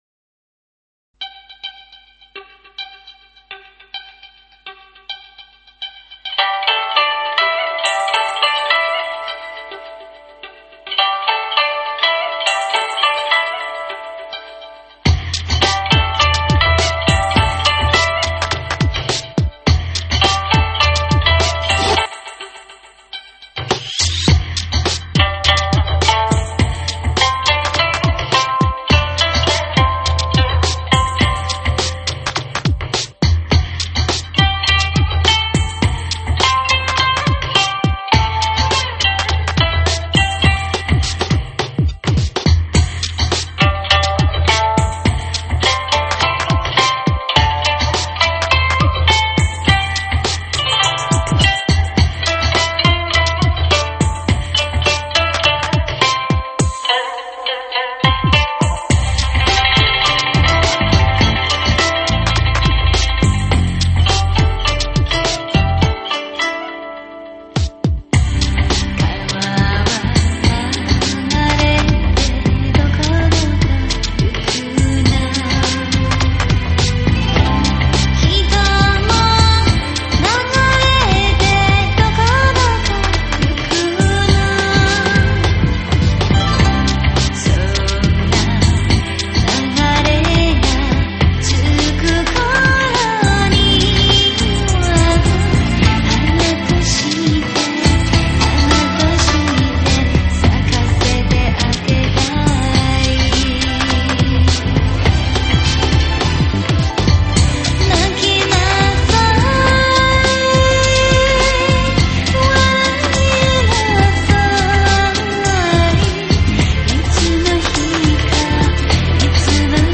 音乐类型:NEWAGE  新民乐
毕竟琵琶在每首曲子里不一定都是主题地位，这会给某些听众带来困惑。